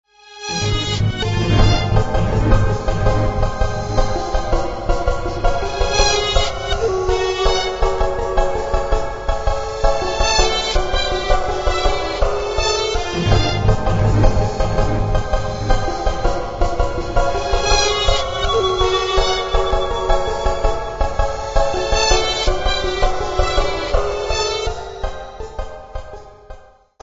new electronic german music